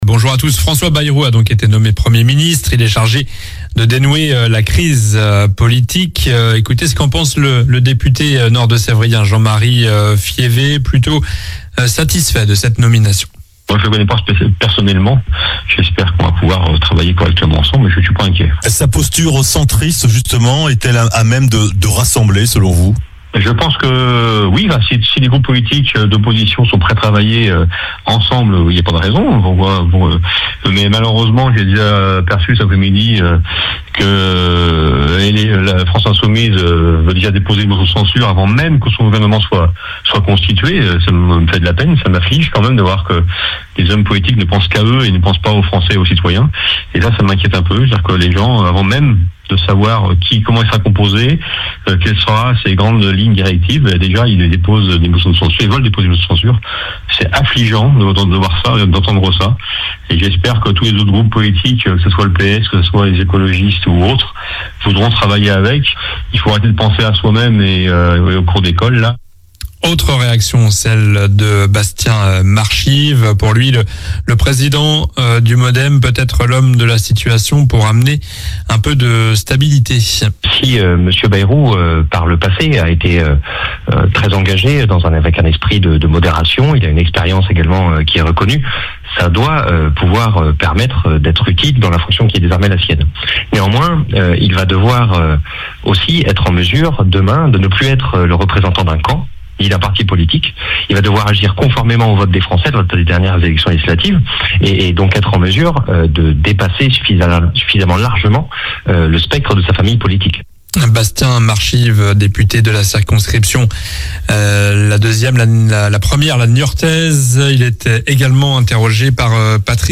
Journal du samedi 14 décembre (matin)